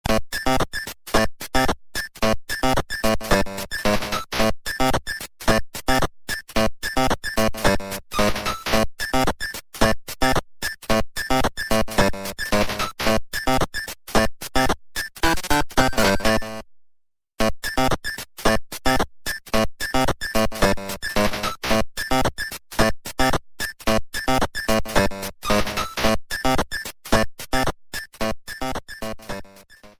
theme
Self-recorded